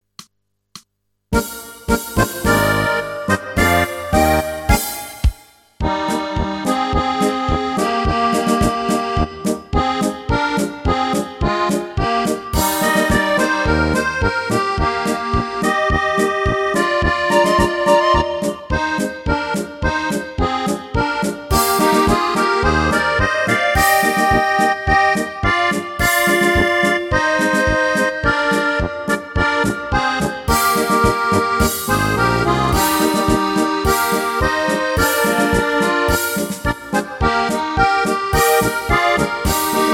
Rubrika: Národní, lidové, dechovka
- směs - polka